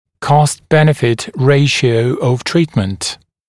[kɔst-‘benɪfɪt ‘reɪʃɪəu əv ‘triːtmənt][кост-‘бэнифит ‘рэйшиоу ов ‘три:тмэнт]соотношение стоимости лечения и выгоды от него